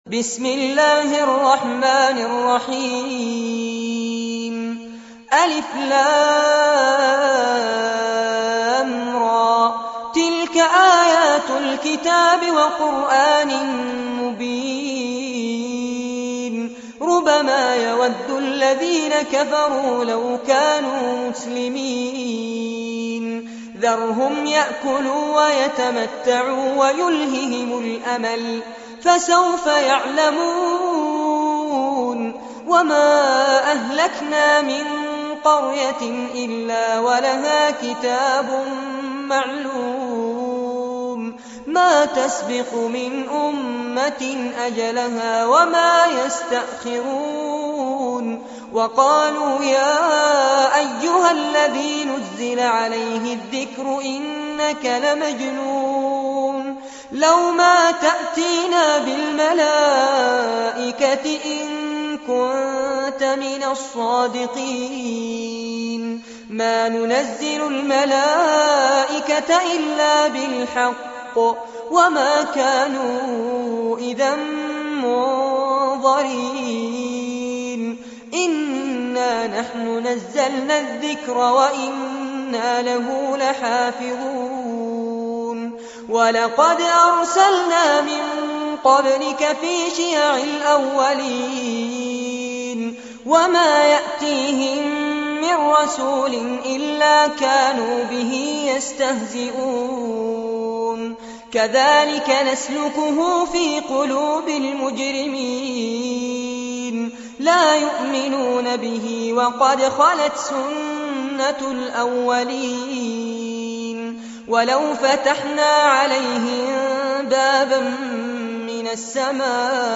سورة الحجر- المصحف المرتل كاملاً لفضيلة الشيخ فارس عباد جودة عالية - قسم أغســـــل قلــــبك 2